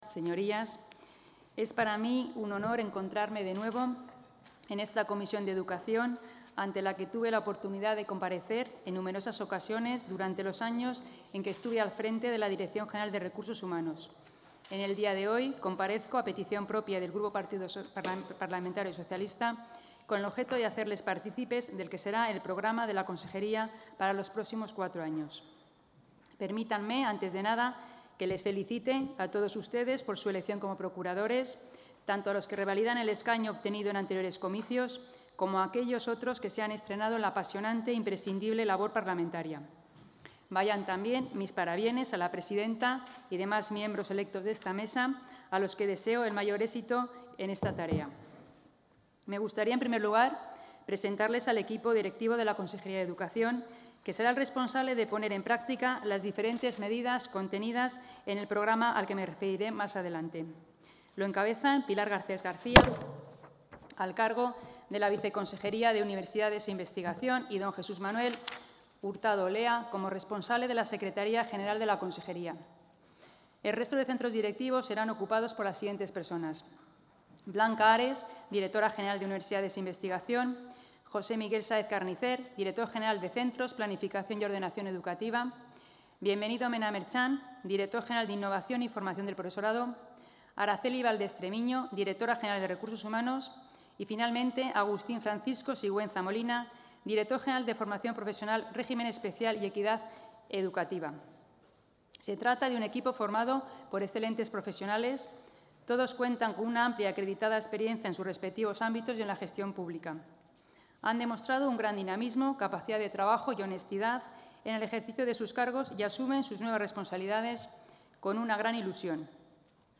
La consejera de Educación, Rocío Lucas, ha comparecido esta tarde en las Cortes de Castilla y León para detallar los proyectos...
Audio comparecencia.